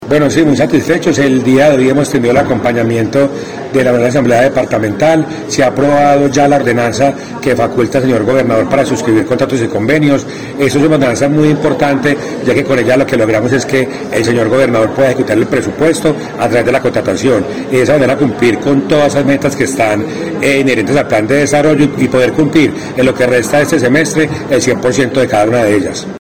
Audio de: Secretario Jurídico y de Contratación de la Gobernación, Julián Mauricio Jara Morales